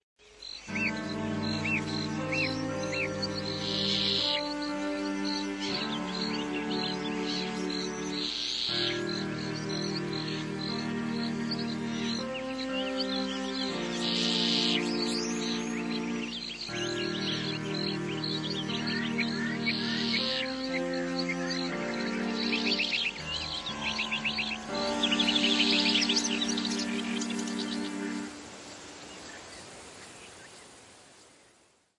远处的打雷声
描述：下雨前的闷热天气，记录远处的打雷声
标签： 下雨前 打雷声 轰隆 远处
声道立体声